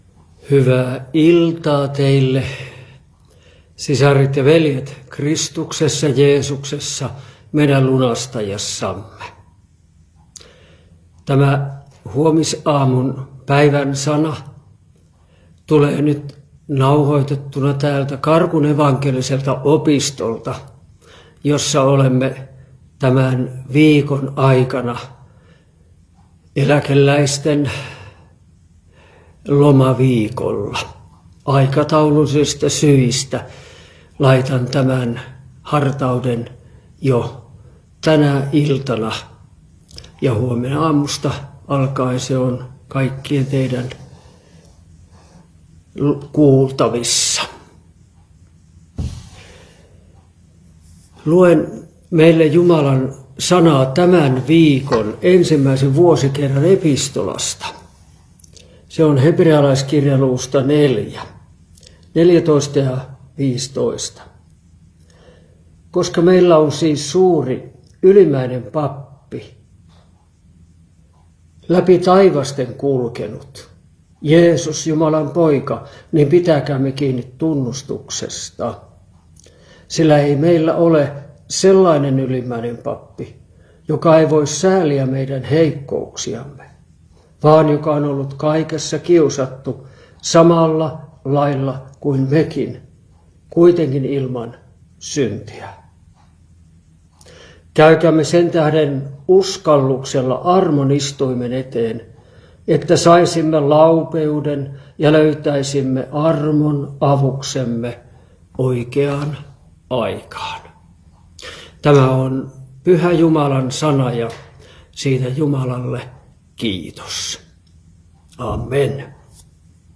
Karkku